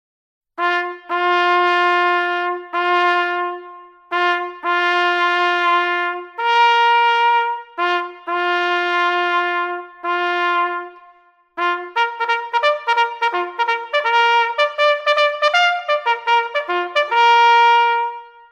Эти треки воспроизводят настоящие горны и трубы, используемые для утреннего подъёма солдат.
Традиционные мелодии для подъема солдат в Англии, Австралии, Канаде и Новой Зеландии: